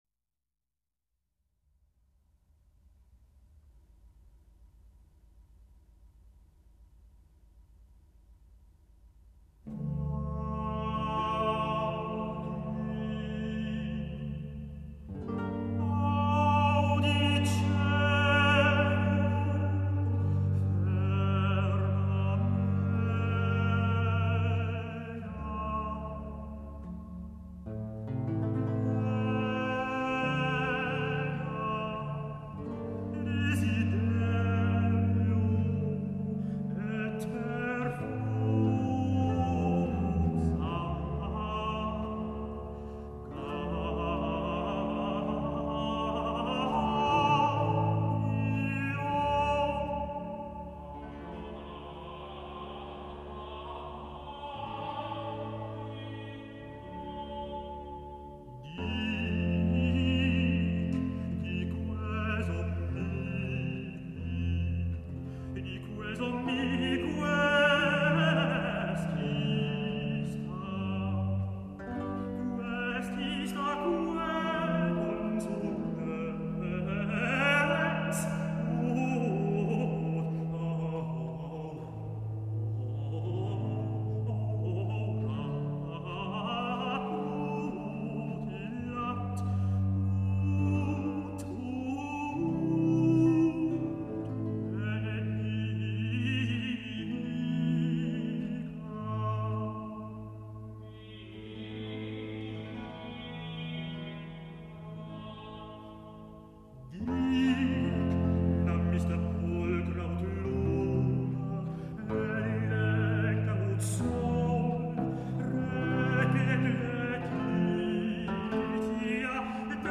Monteverdi, Audi coelum (mottetto dal Vespro 1610).mp3 — Laurea Magistrale in Culture e Tradizioni del Medioevo e del Rinascimento